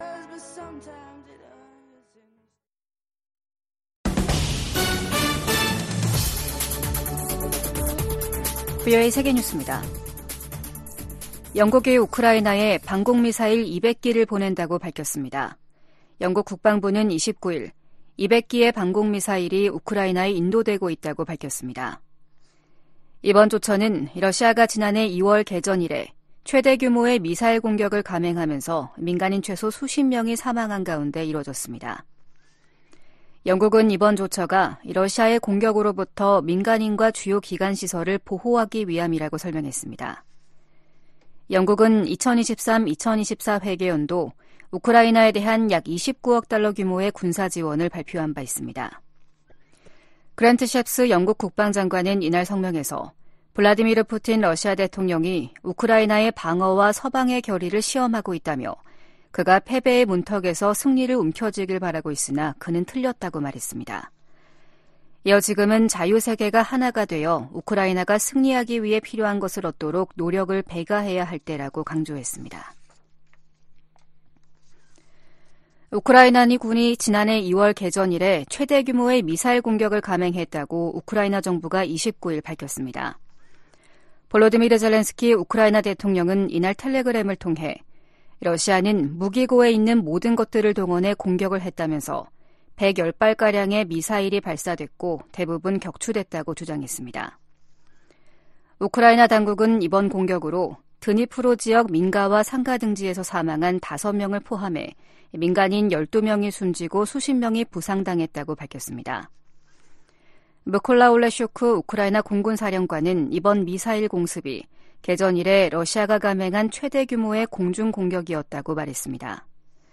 VOA 한국어 아침 뉴스 프로그램 '워싱턴 뉴스 광장’ 2023년 12월 30일 방송입니다. 팔레스타인 무장정파 하마스가 북한의 유탄발사기 부품을 이용해 살상력이 큰 신무기를 만든 것으로 확인됐습니다. 미국 전문가들은 김정은 북한 국무위원장의 ‘전쟁준비 완성’ 지시에, 핵 도발은 김씨 정권의 종말을 뜻한다고 경고했습니다. 미국 내 일각에선 북한 비핵화 대신 북한과의 핵무기 감축 협상이 현실적인 방안이라는 주장이 나오고 있습니다.